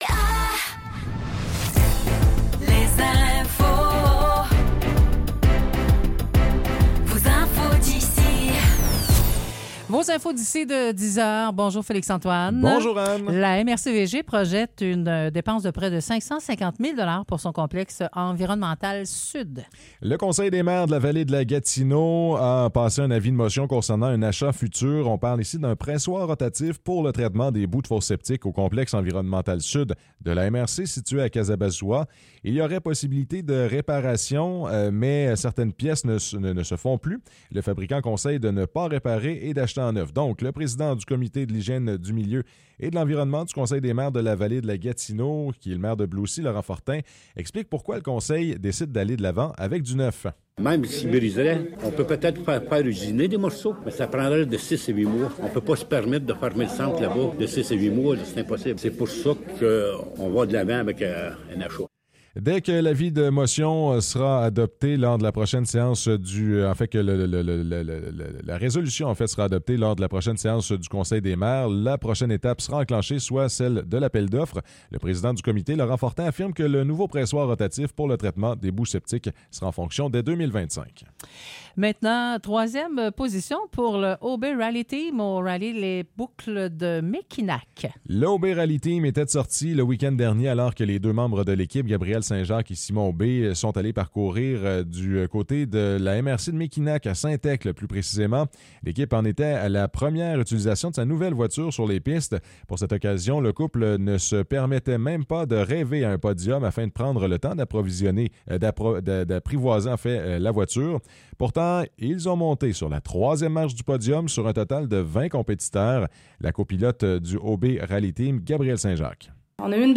Nouvelles locales - 23 janvier 2024 - 10 h